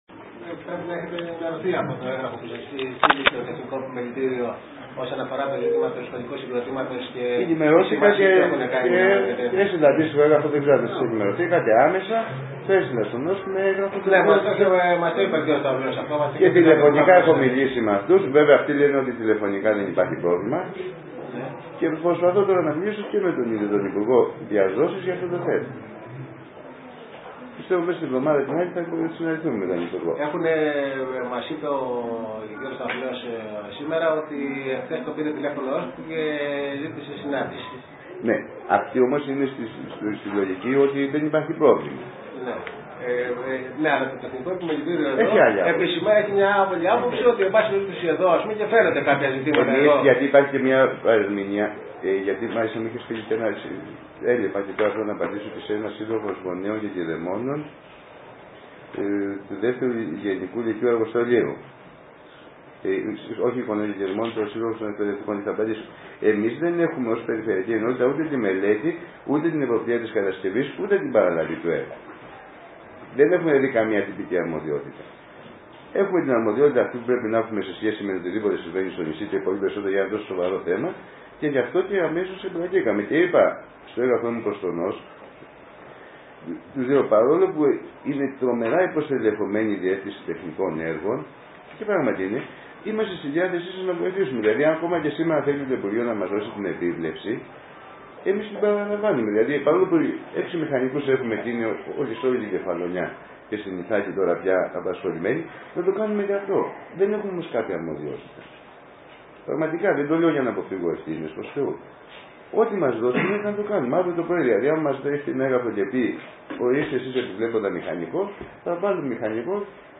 Υπήρξε κι από τις δυο πλευρές μια εποικοδομητική συζήτηση (θα την ακούσετε), όπου ο κ. αντιπεριφερειάρχης άνοιξε τα χαρτιά του ως προς τις προθέσεις.
Ακούστε τη συζήτηση με τον αντιπεριφερειάρχη: ΣΥΝΟΜΙΛΙΑ ΜΕ ΤΟΝ ΑΝΤΙΠΕΡΙΦΕΡΕΙΆΡΧΗ
ΣΥΝΟΜΙΛΙΑ-ΜΕ-ΤΟΝ-ΑΝΤΙΠΕΡΙΦΕΡΕΙΆΡΧΗ.mp3